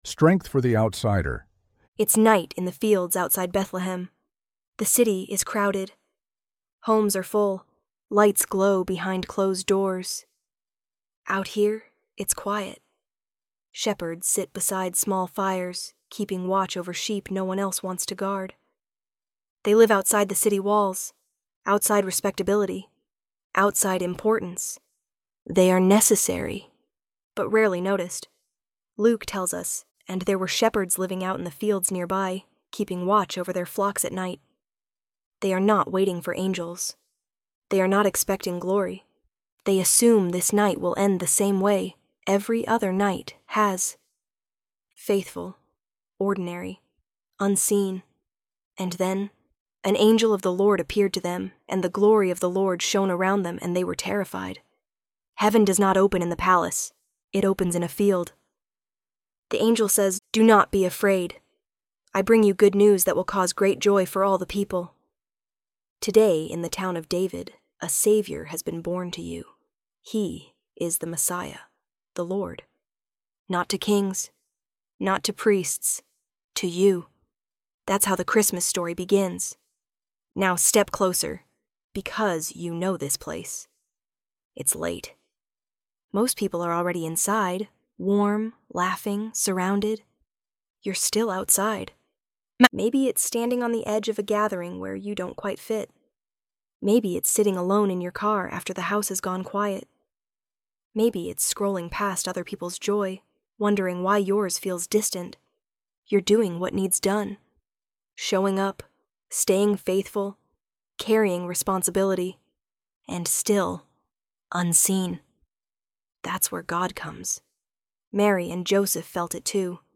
ElevenLabs_God_Sees_the_Unnoticed.mp3